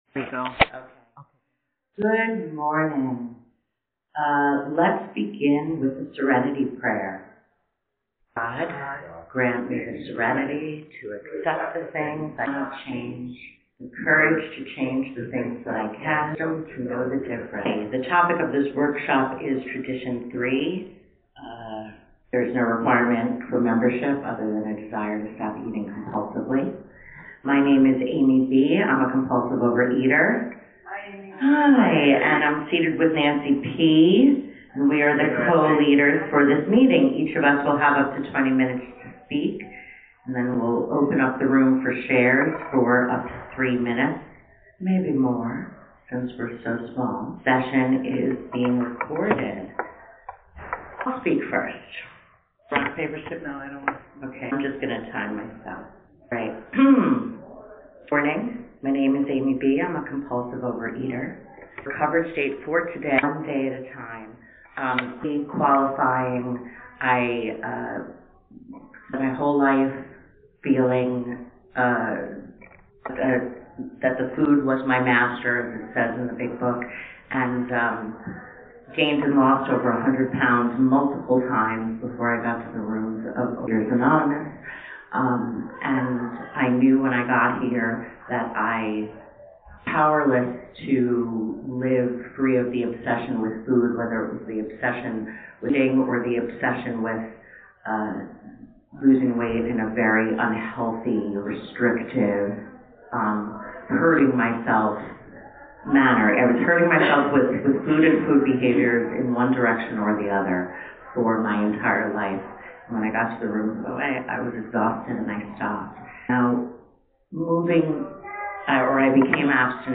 A workshop given at the 2024 OA Region 6 convention, held in October in Nashua, NH, US.